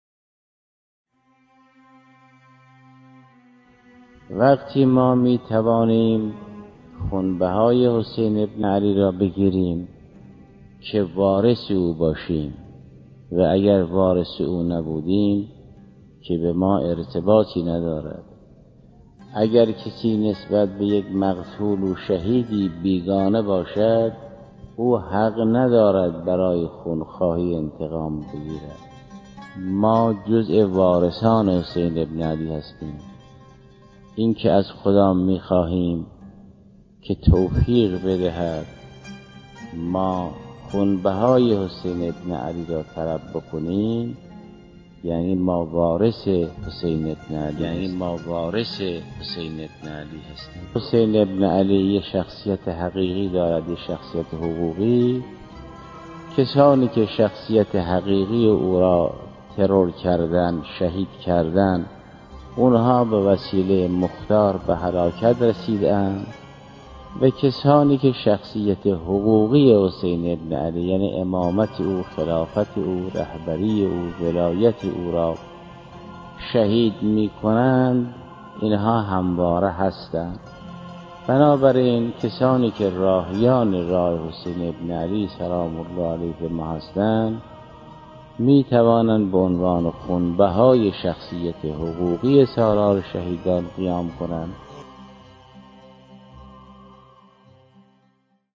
پادپخش بیانات آیت الله جوادی آملی در خصوص "انتقام خون امام حسین علیه السلام"